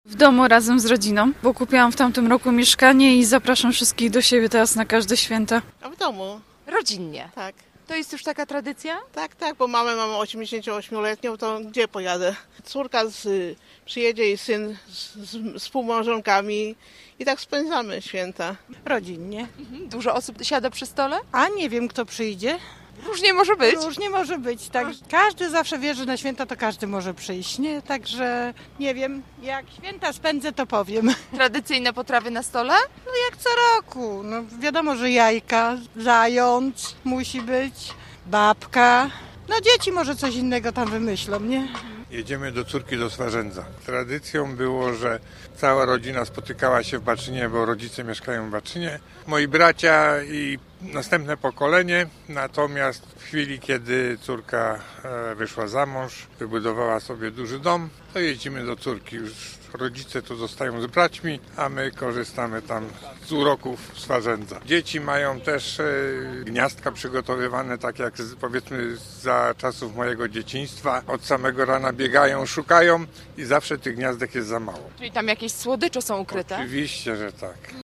Zapytaliśmy gorzowian, jak oni spędzają ten wyjątkowy czas.